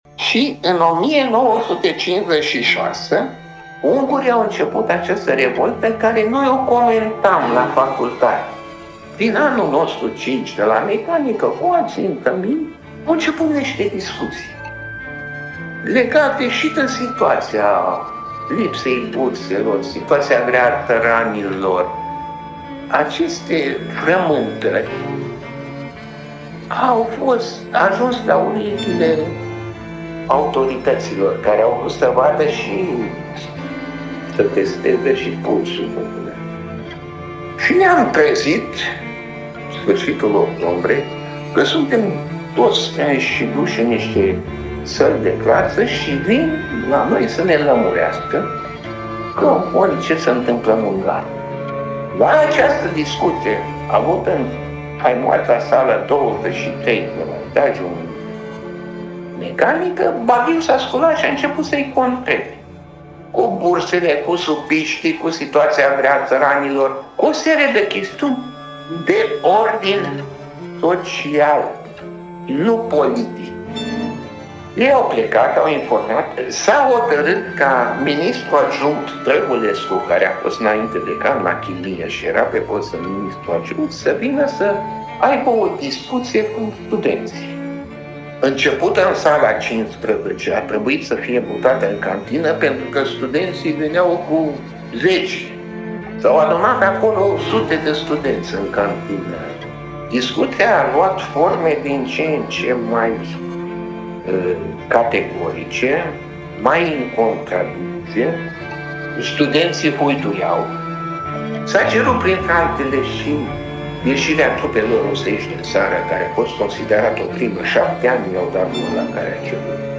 Fragment din interviul inginerului